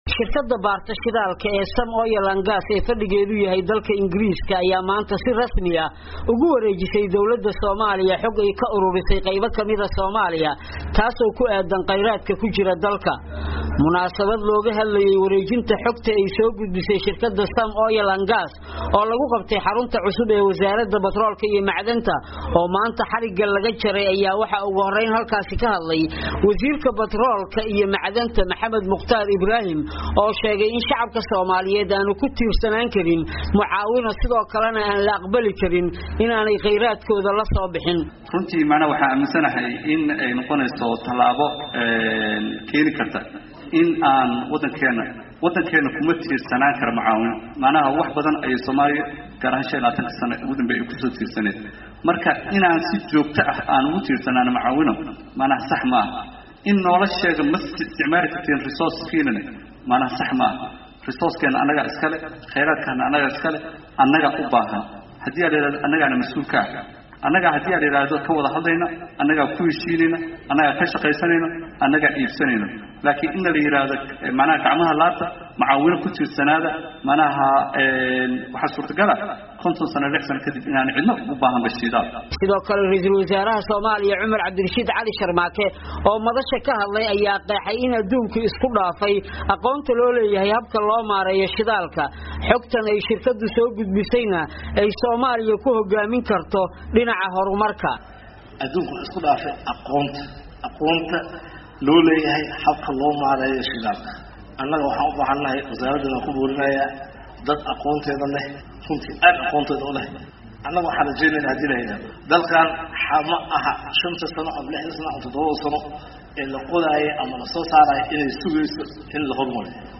Warbixin